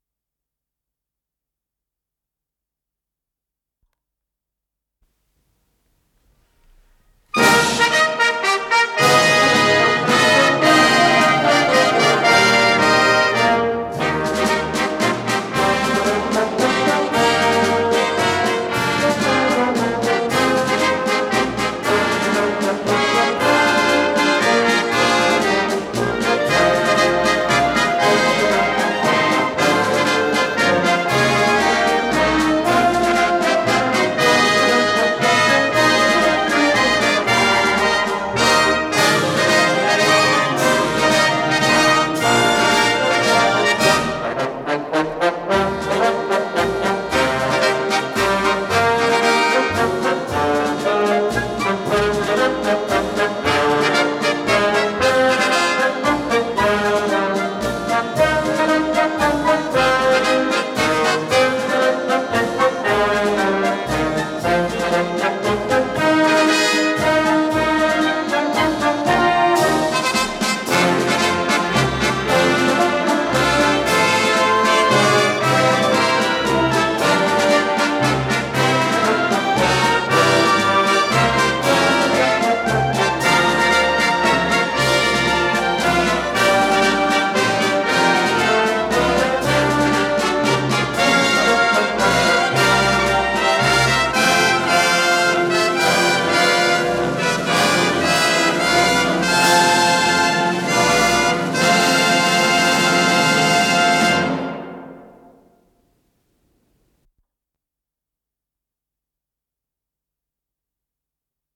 с профессиональной магнитной ленты
ПодзаголовокДля духового оркестра, фа мажор
ВариантДубль моно